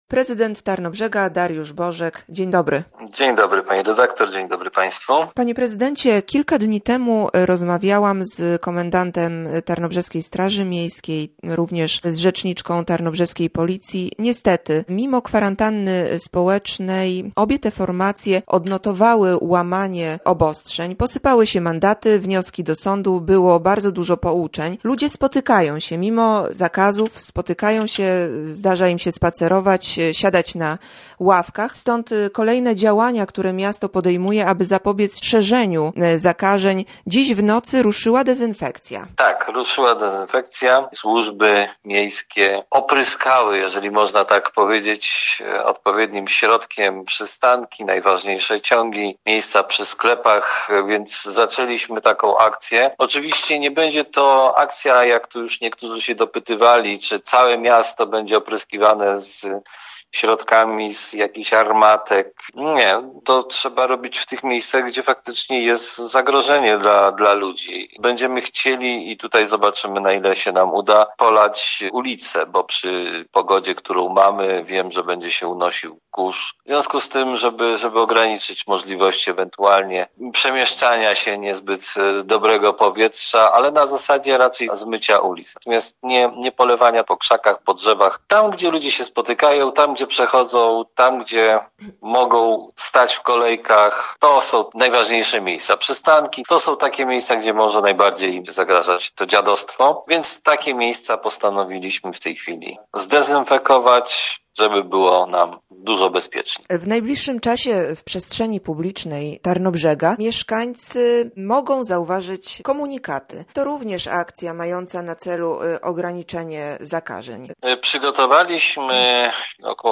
Rozmowa z prezydentem Tarnobrzega, Dariuszem Bożkiem.